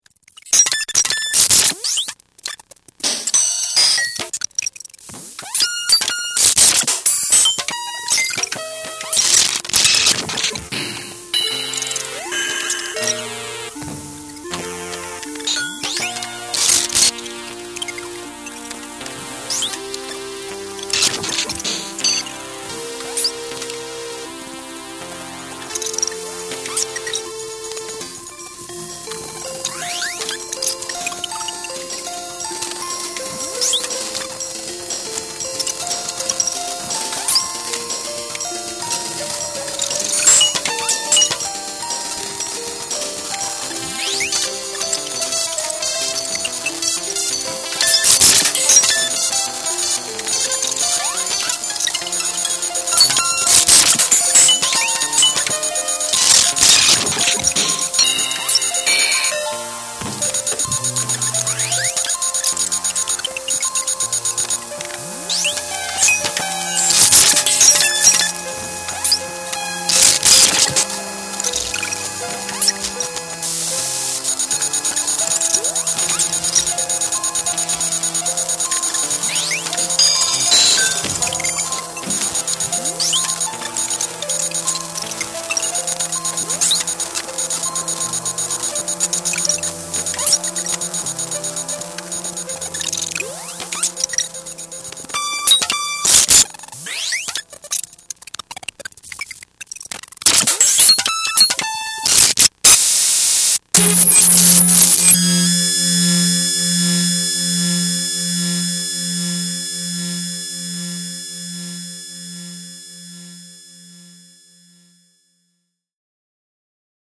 This was one of my first circuit bends-it makes all sorts of glitched rhythms and noises via a single momentary switch.
Here is a bunch of bent melodies together.